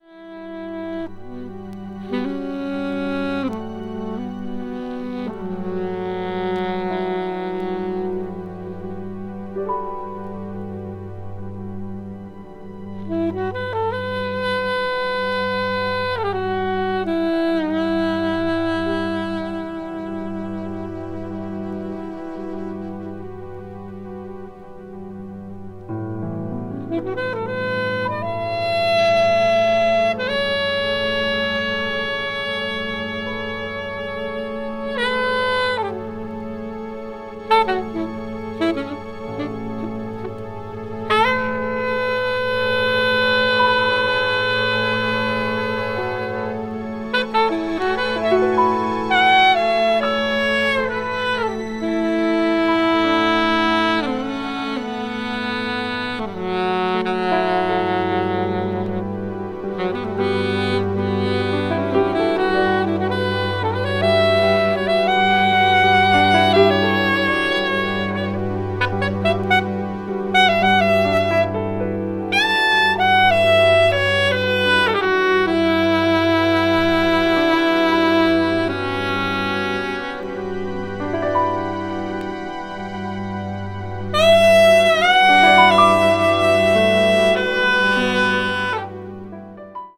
trombonist
joined on Poly Moog, Multimoog and Micromoog.